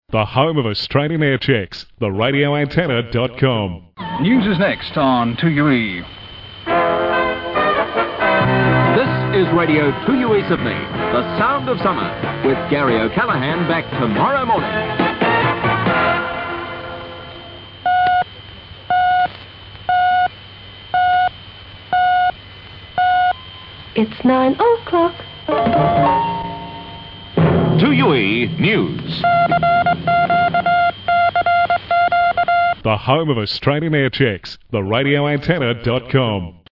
RA Aircheck – 2UE Gary O’Callaghan promo 1972
well what a blast hearing that quaint little jingle before the news at the top of the hour, it must have been around right up until 1980 at least as it was still used when I was in High School